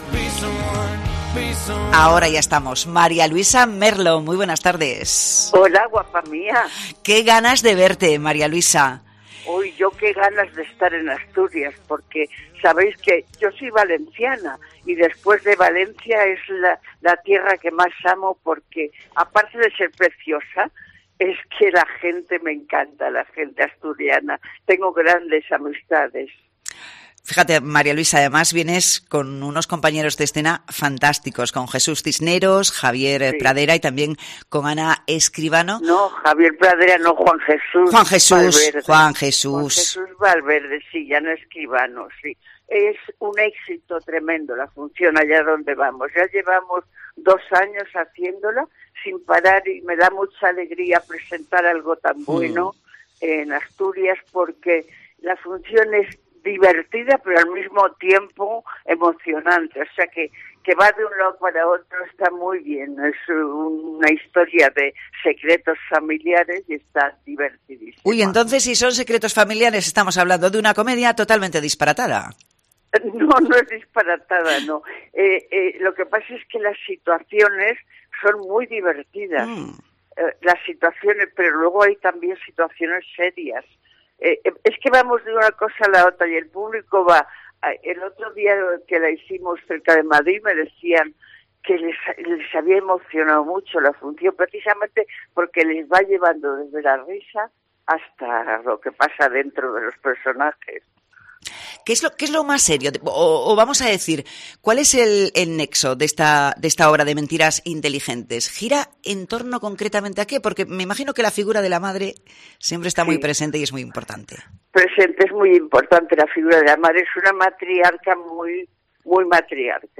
Entrevista con María Luisa Merlo